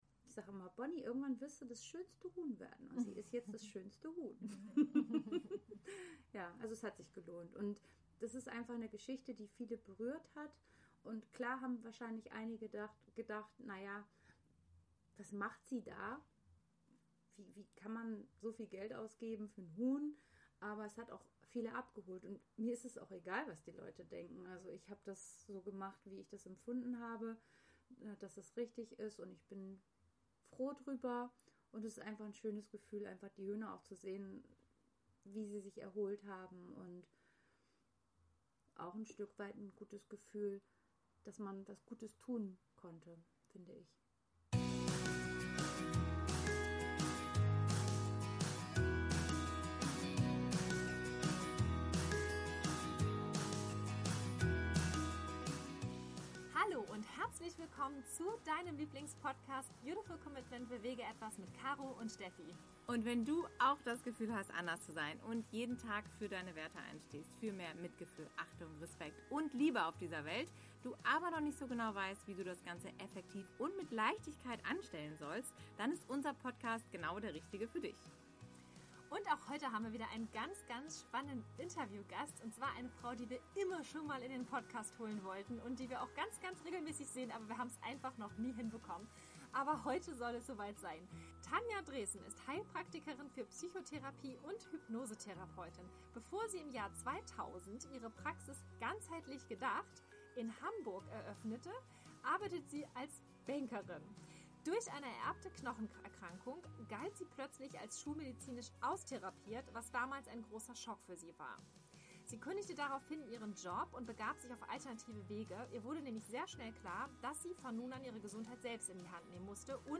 148 Lucky (the) Hen - Ein Huhn reist um die Welt - Interview Special